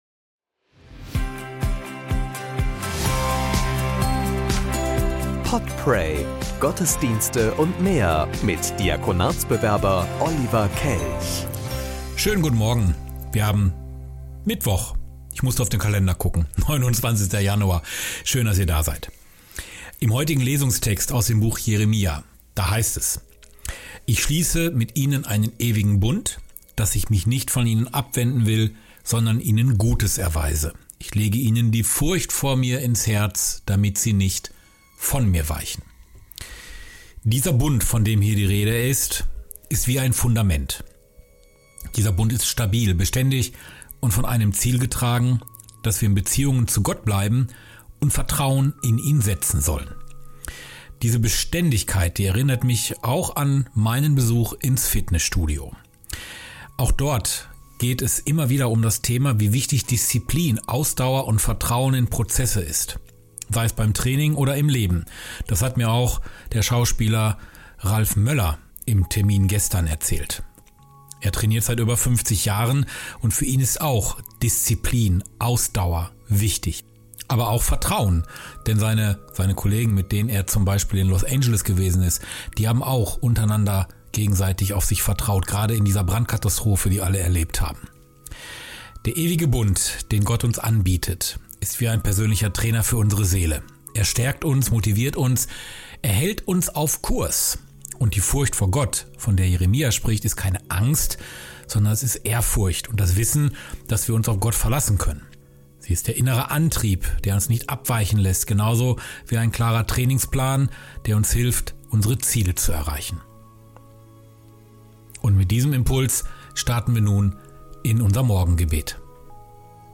Morgengebet und Impuls am 29.1.2025